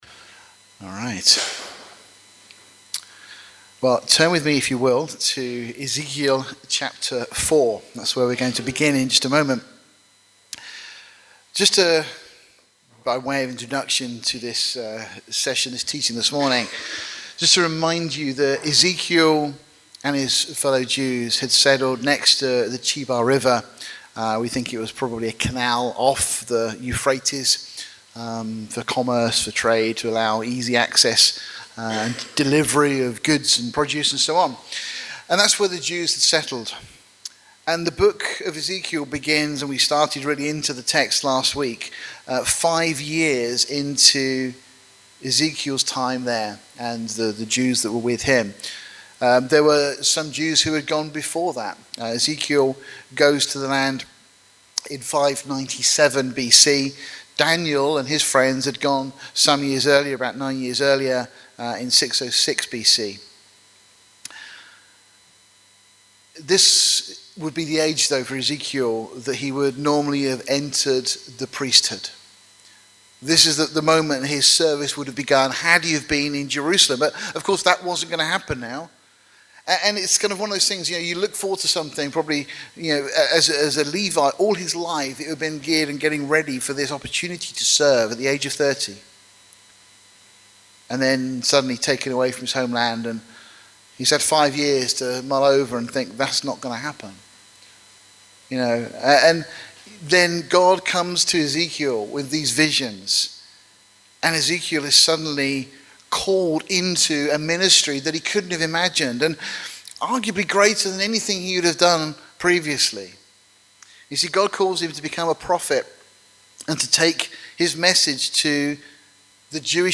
Ezekiel-Ch-456_CCP-Sermon_2025-10-05.mp3